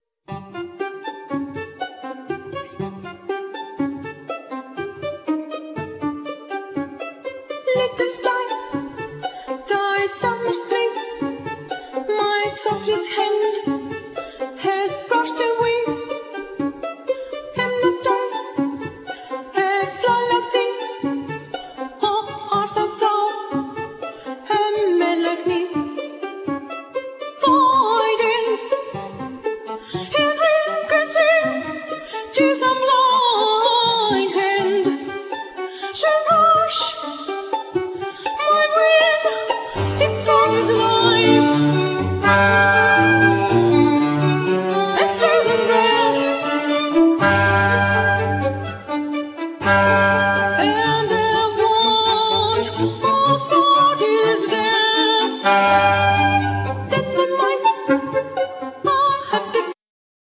Voice
Flute
Clarinet
Keyboards
Violin
Cello